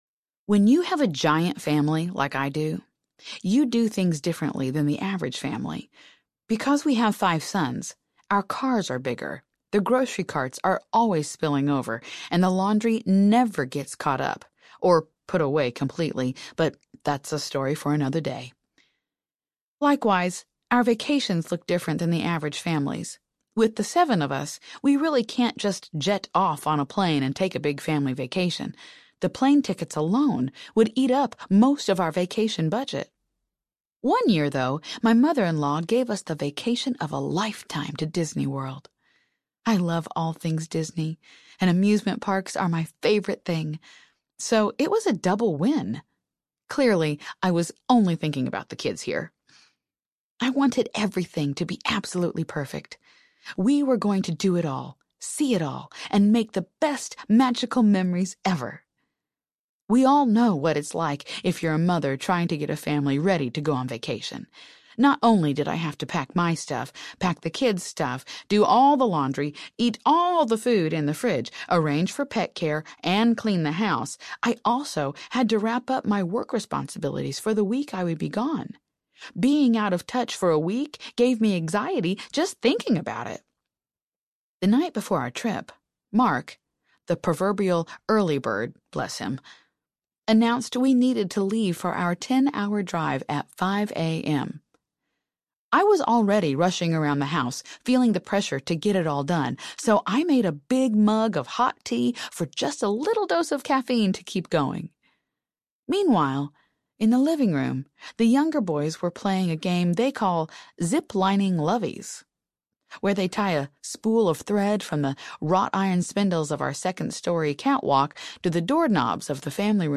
Fierce Faith Audiobook
Narrator